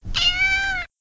tone_cat_meow.mp3